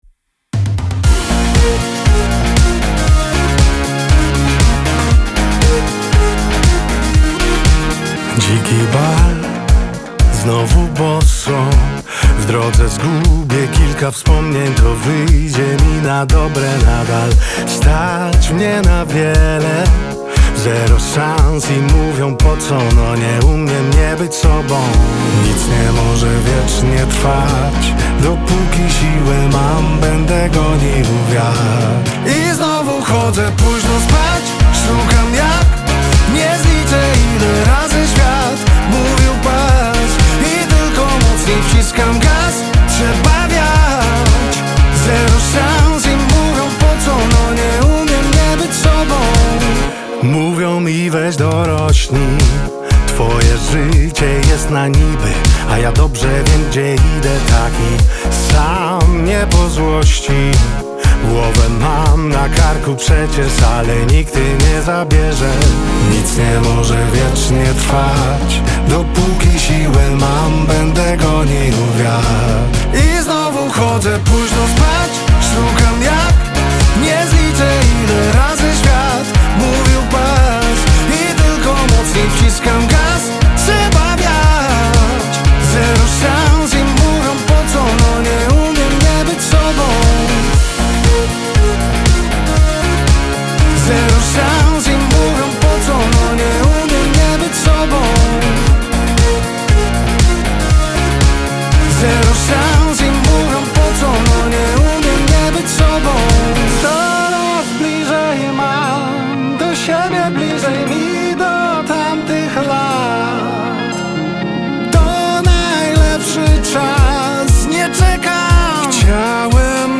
Singiel (Radio)
Pop-rockowy mega hit!
Za przebojowe, potężne i dynamiczne brzmienie